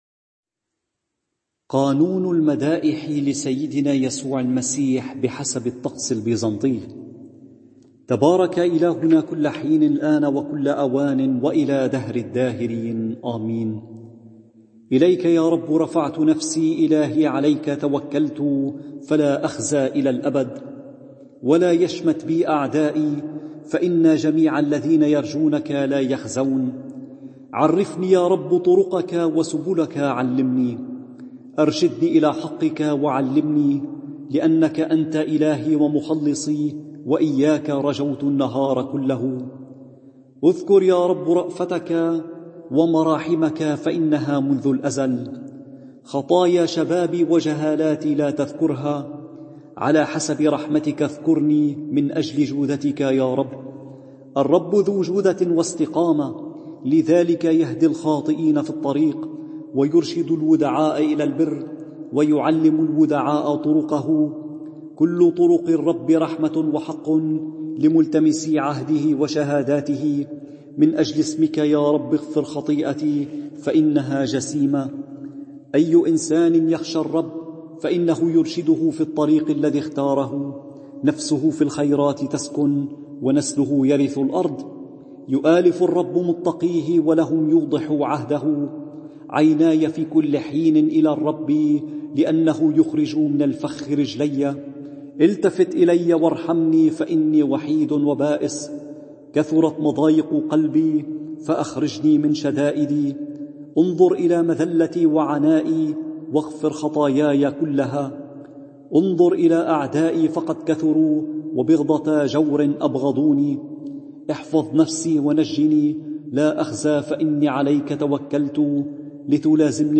قانون مدائح يسوع رهبان